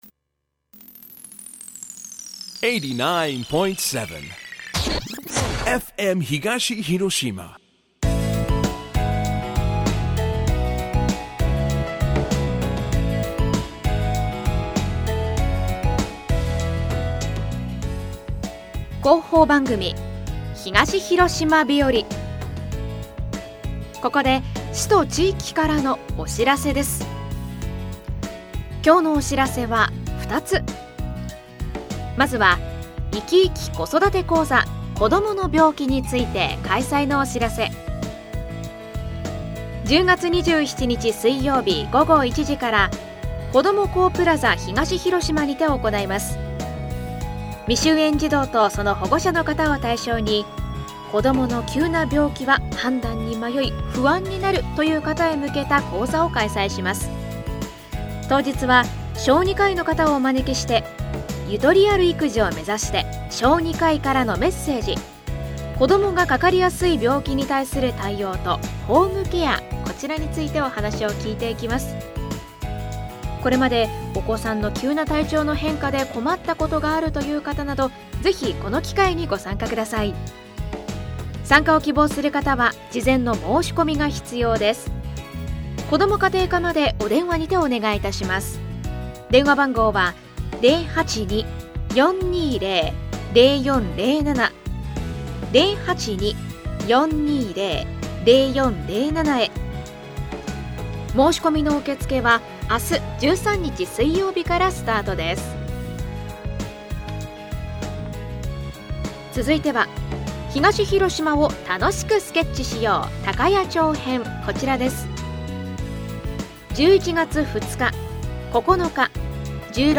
広報番組「東広島日和」です。今日は「いきいき子育て講座」「東広島を楽しくスケッチしよう」